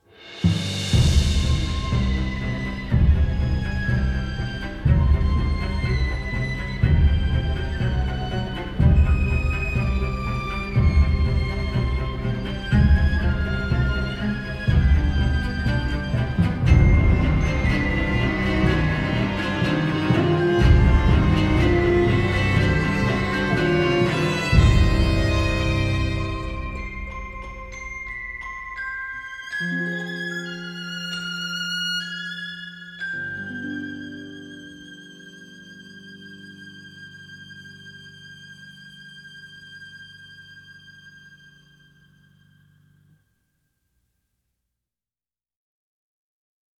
conducts a large orchestra recorded in Los Angeles.